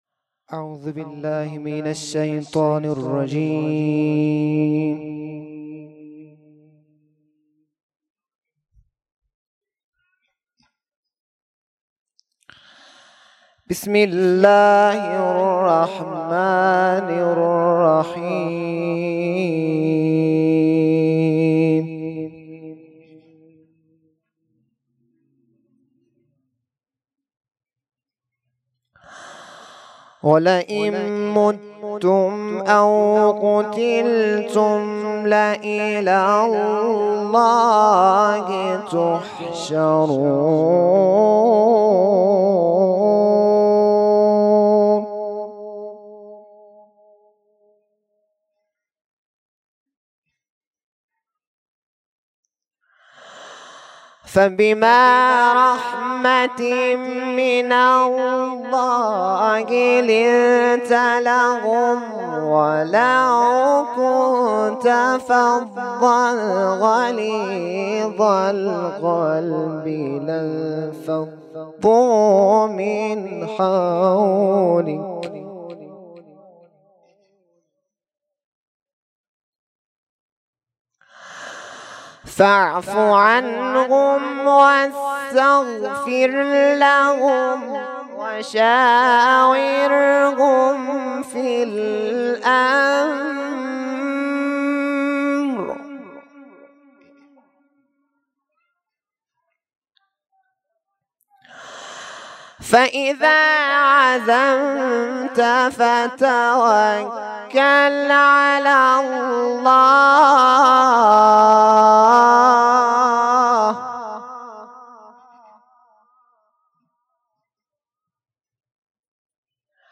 شب دوم محرم 1440
قرائت قرآن